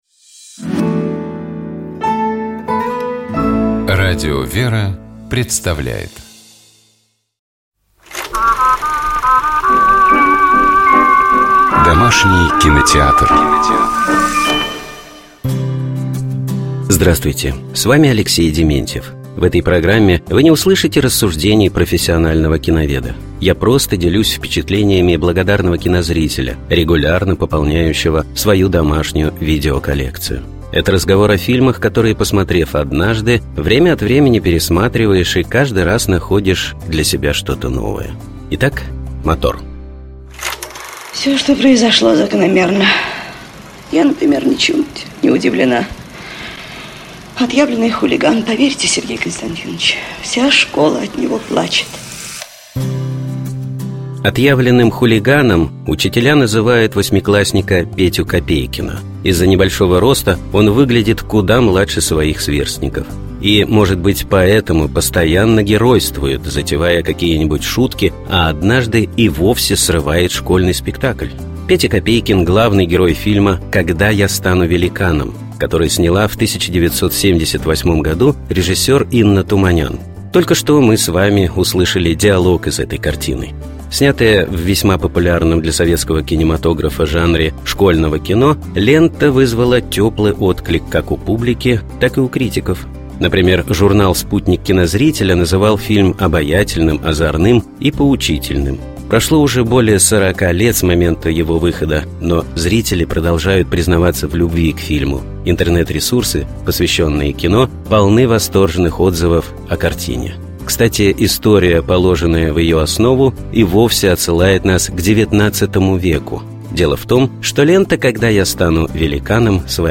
Только что мы с вами услышали диалог из этой картины.